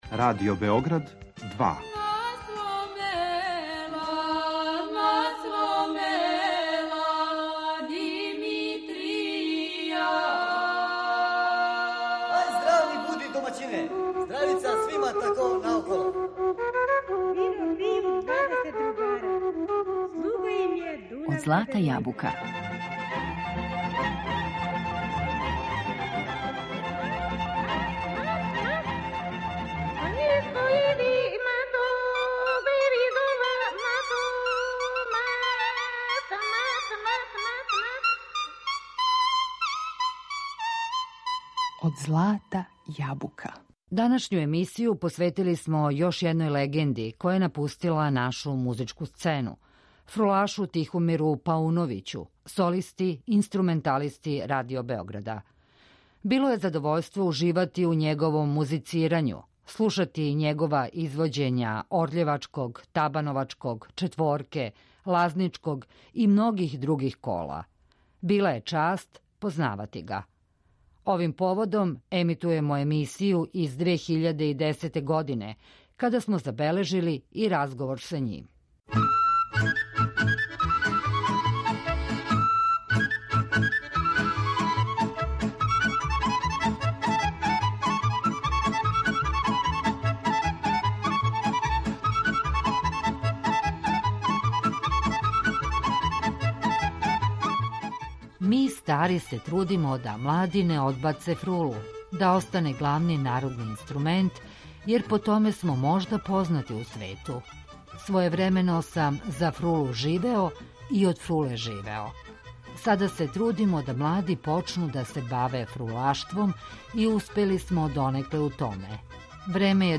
фрулашу